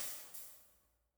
OPENHAT - BUTTERFLY EFFECT.wav